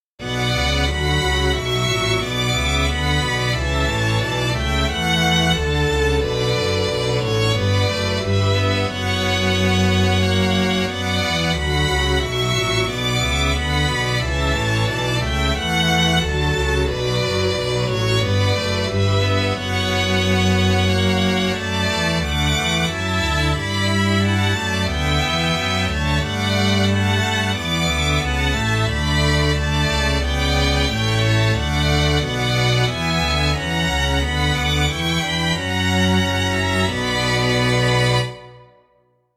・J.Sバッハ「まぶねのかたえに（BWV469）」（弦楽合奏）